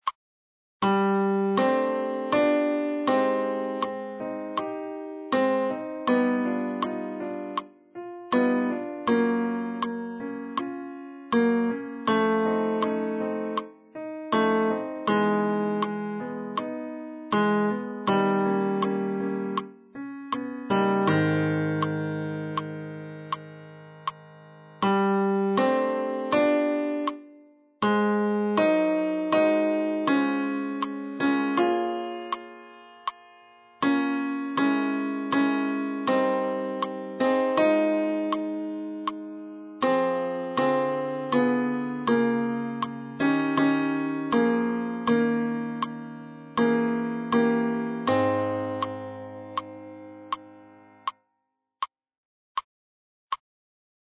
Bonjour – tenor